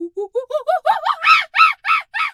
monkey_2_chatter_scream_04.wav